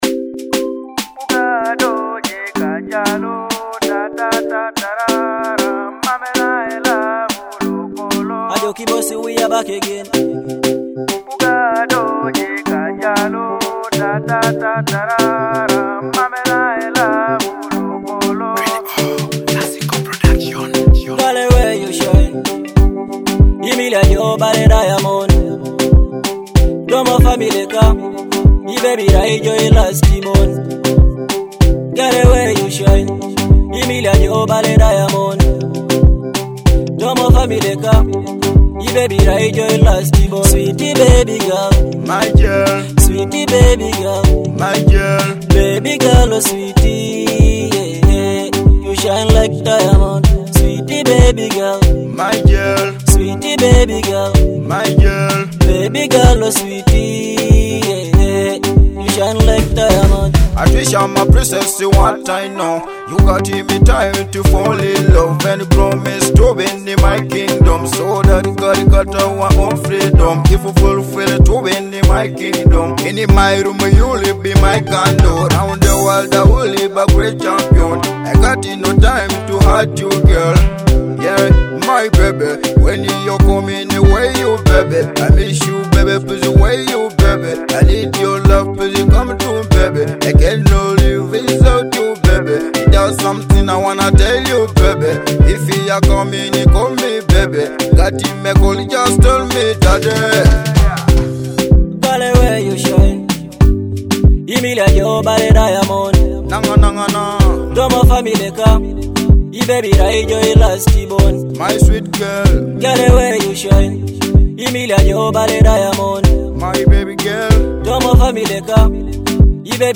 Teso music